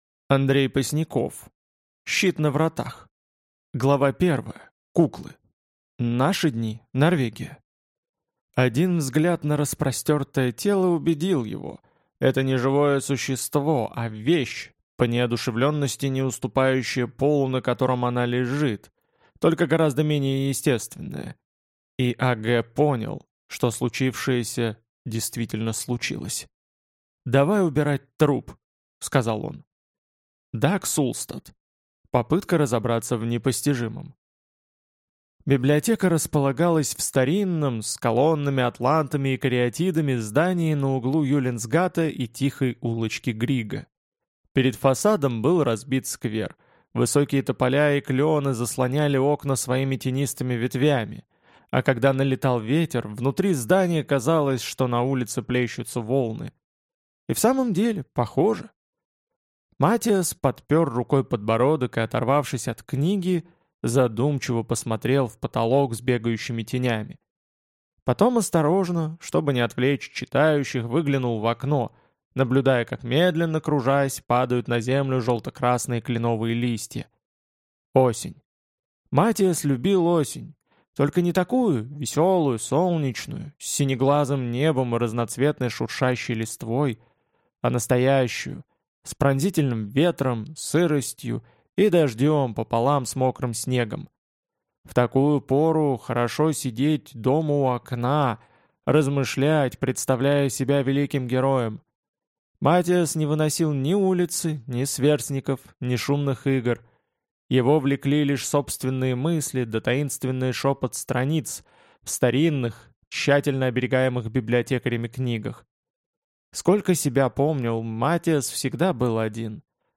Аудиокнига Щит на вратах | Библиотека аудиокниг